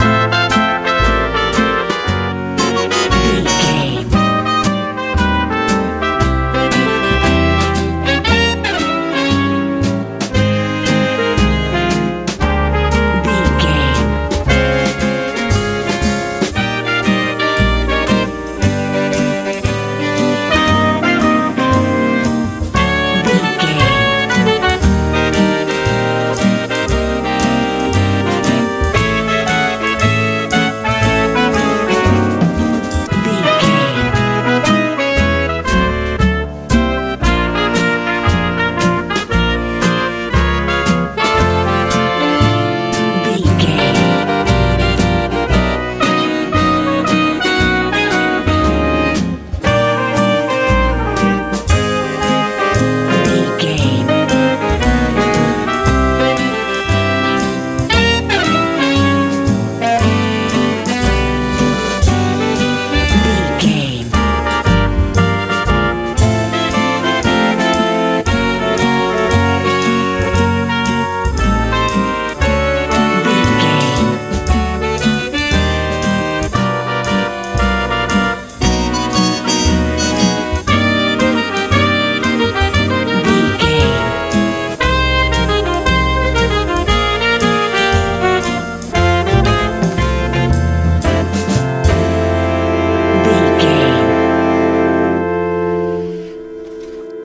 crooner jazz feel
Ionian/Major
happy
wholesome
bass guitar
drums
horns
acoustic guitar
lively
light
joyful